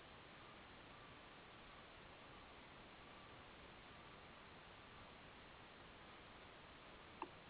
A 5 boomer, 4 boomer and a lot of 3 boomer calls.